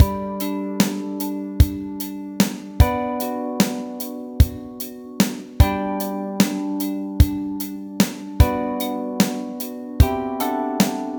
We hebben blijkbaar te maken met een halve tel ergens in het stuk.
7/8 maat
7-8-loop.wav